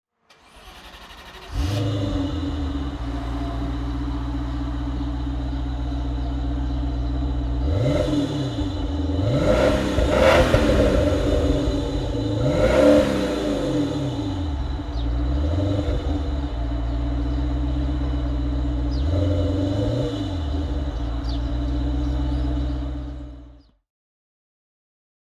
Maserati Shamal (1989) - V8 engine with 3217 cc displacementMaserati Shamal (1989) - V8 engine with 3217 cc displacement
Maserati 3200 GT (1999) - Starten und Leerlauf